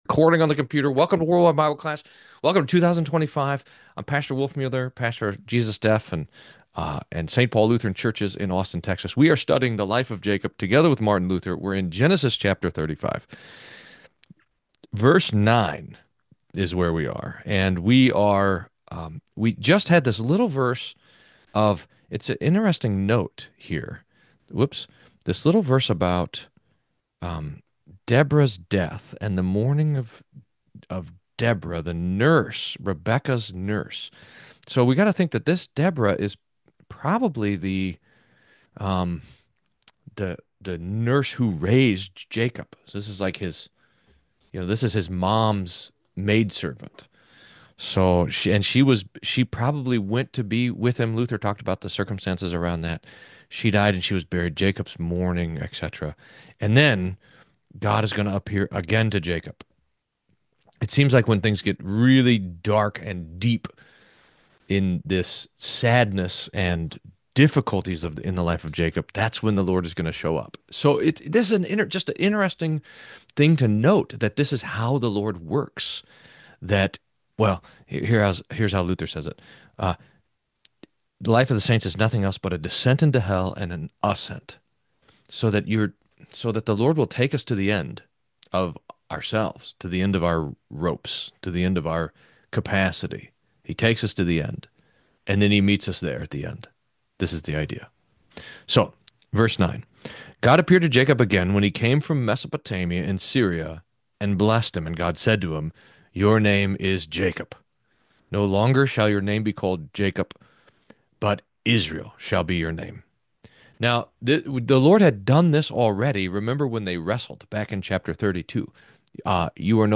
World-Wide Bible Class